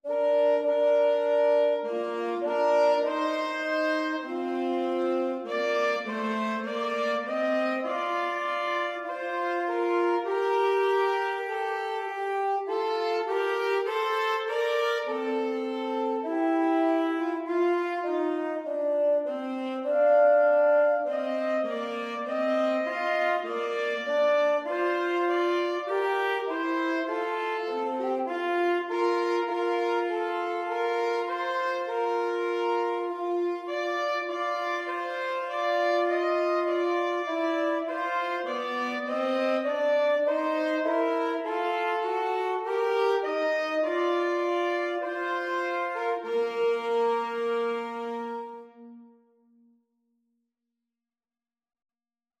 Christmas Christmas Alto-Tenor-Sax Duet Sheet Music O Come All Ye Faithful
Free Sheet music for Alto-Tenor-Sax Duet
4/4 (View more 4/4 Music)
Bb major (Sounding Pitch) (View more Bb major Music for Alto-Tenor-Sax Duet )